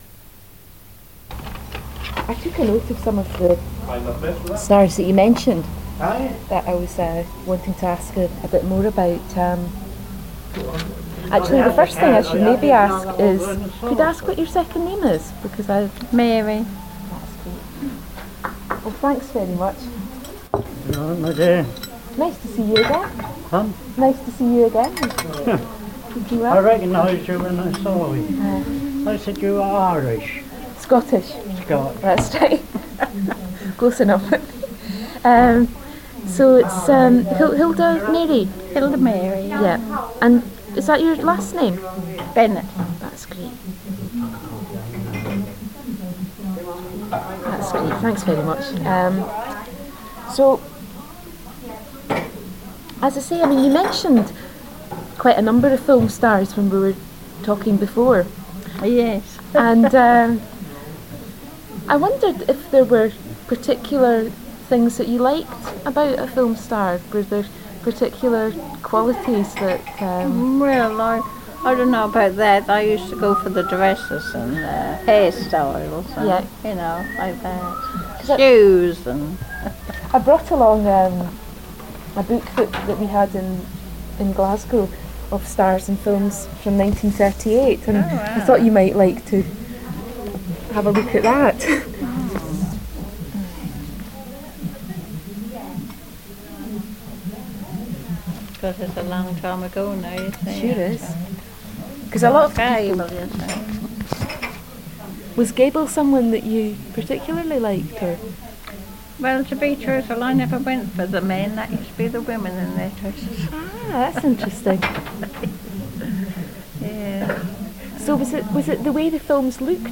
Sound Quality: Fair;
******************************************************** [Start of Tape One] [Start of Side A] [recording starts; voices in background] VB: I took a note of some of the stars that you mentioned.